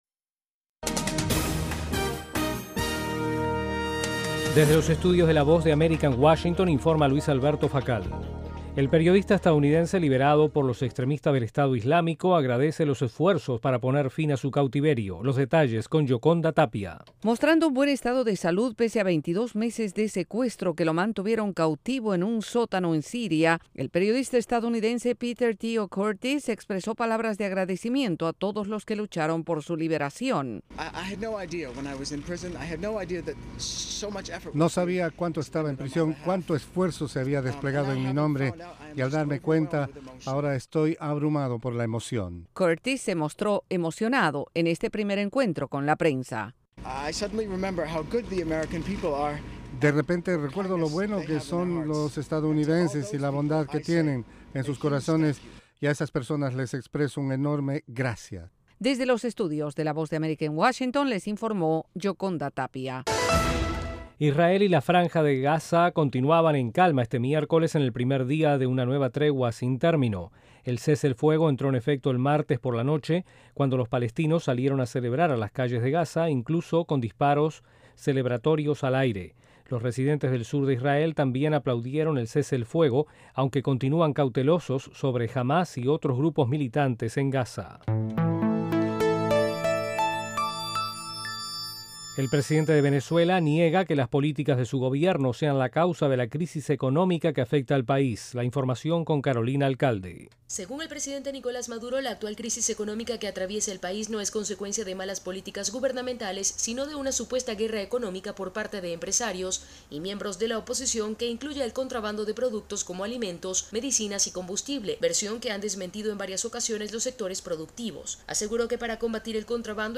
En cinco minutos de duración, Informativo VOASAT ofrece un servicio de noticias que se transmite vía satélite desde los estudios de la Voz de América.